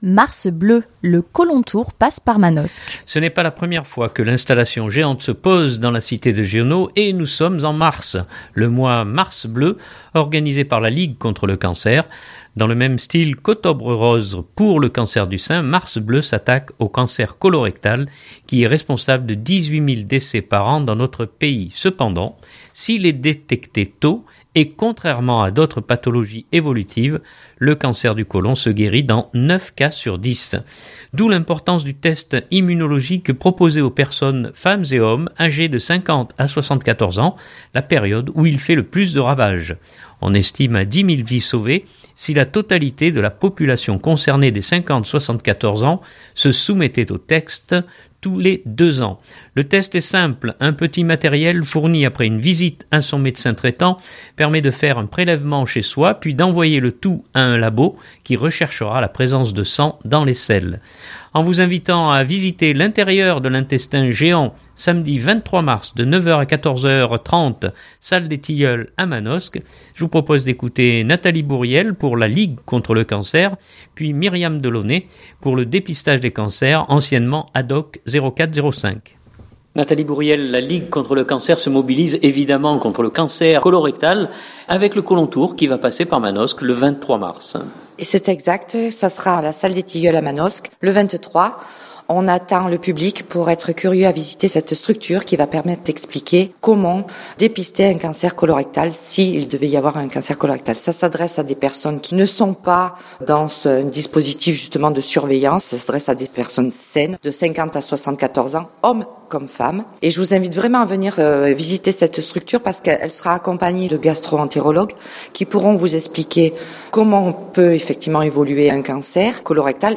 reportage cancer colon 2019-03-19.mp3 (2.58 Mo)